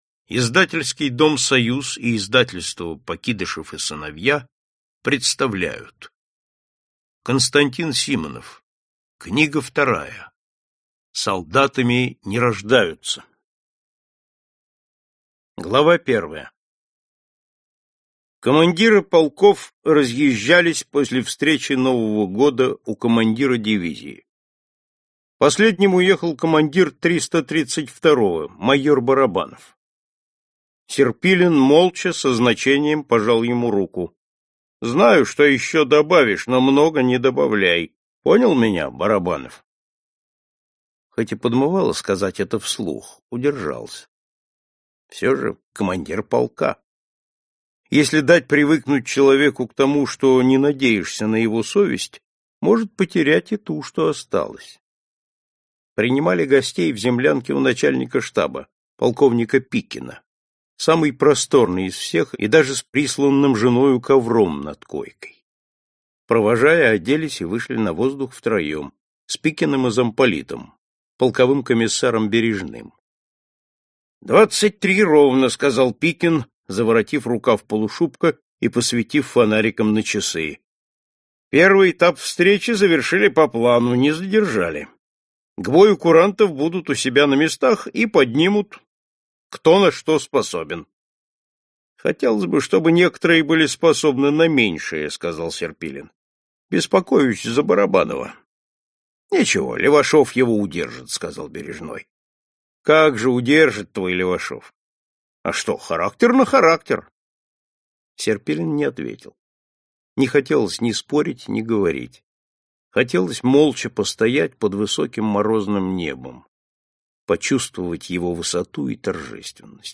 Аудиокнига Солдатами не рождаются | Библиотека аудиокниг